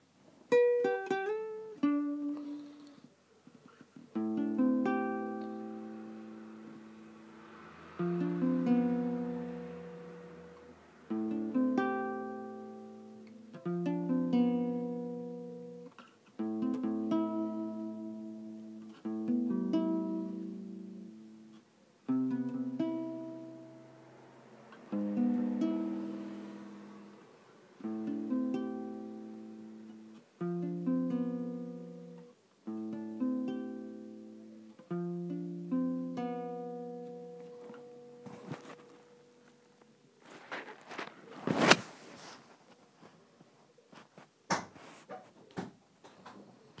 Working on a new song on my twenty quid Argos guitar
75072-working-on-a-new-song-on-my-twenty-quid-argos-guitar.mp3